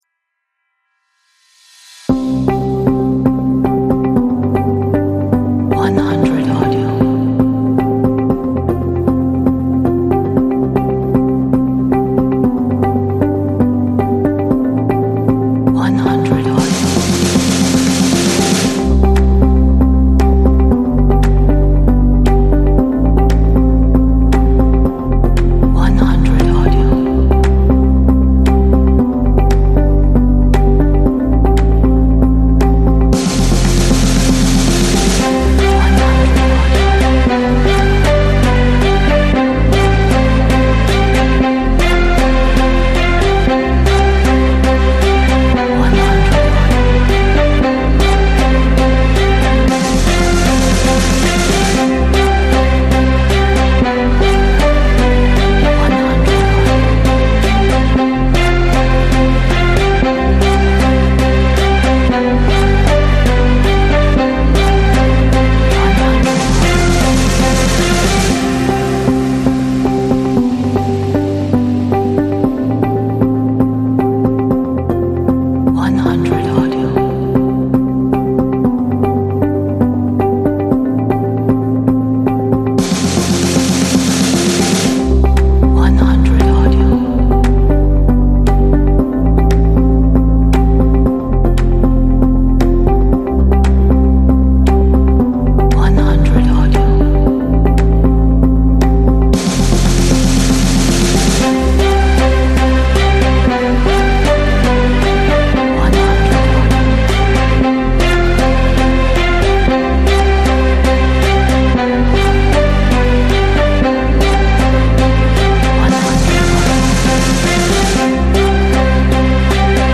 inspiring, motivational